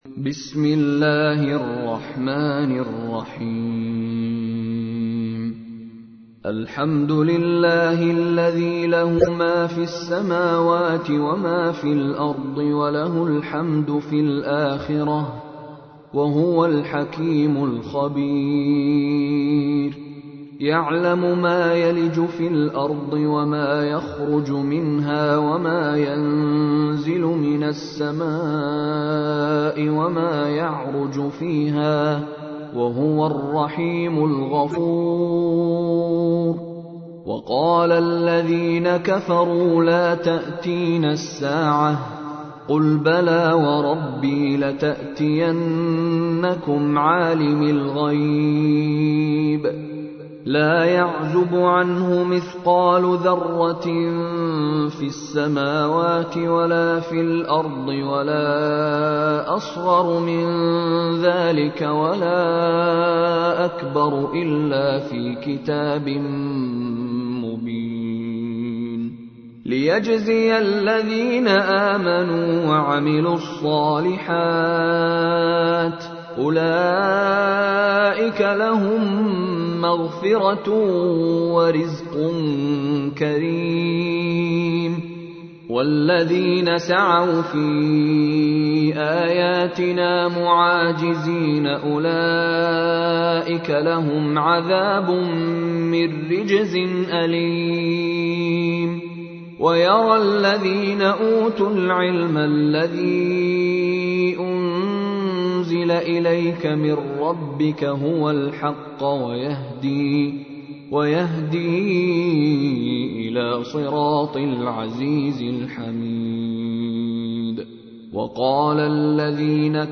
تحميل : 34. سورة سبأ / القارئ مشاري راشد العفاسي / القرآن الكريم / موقع يا حسين